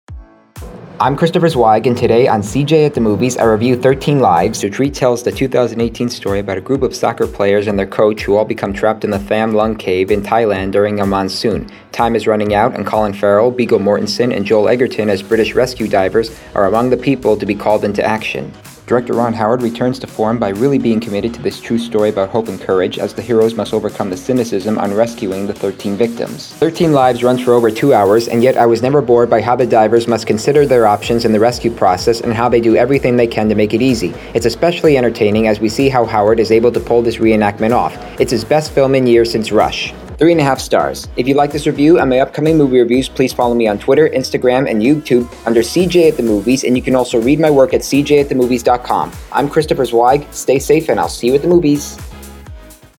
Bullet Train, Thirteen Lives, and Prey Podcast Reviews